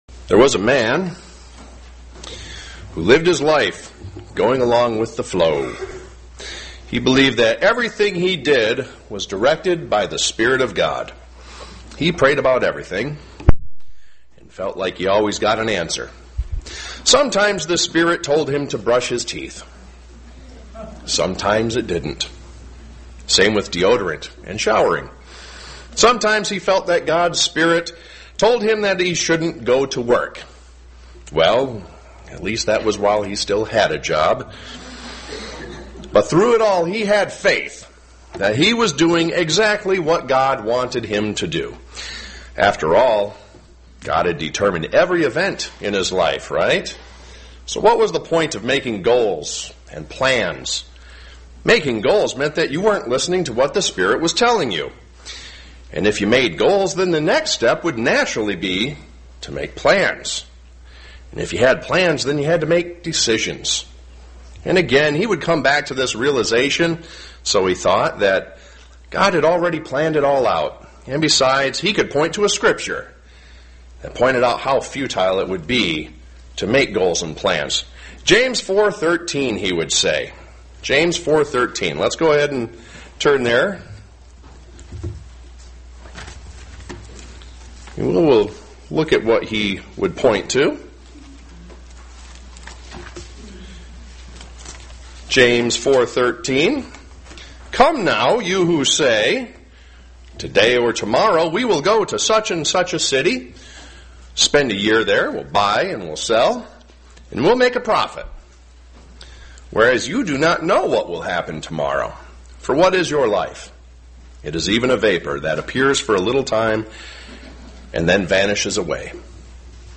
Print Should we be making goals and plans? sermon Studying the bible?
Given in Flint, MI